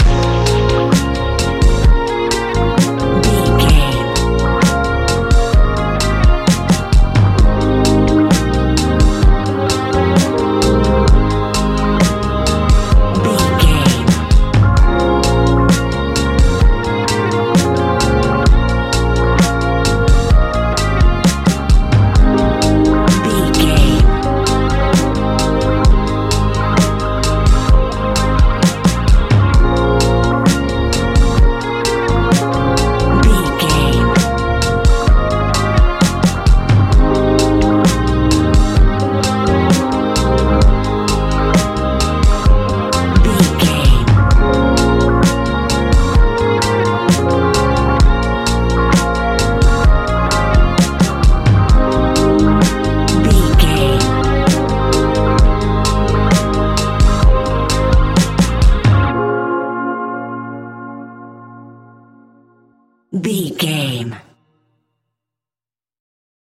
Ionian/Major
C♭
chilled
laid back
Lounge
sparse
new age
chilled electronica
ambient
atmospheric
morphing